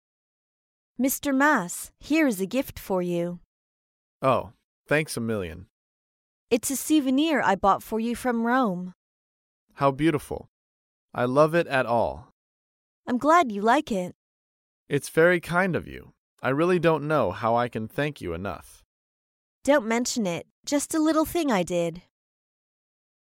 在线英语听力室高频英语口语对话 第110期:收到礼物致谢的听力文件下载,《高频英语口语对话》栏目包含了日常生活中经常使用的英语情景对话，是学习英语口语，能够帮助英语爱好者在听英语对话的过程中，积累英语口语习语知识，提高英语听说水平，并通过栏目中的中英文字幕和音频MP3文件，提高英语语感。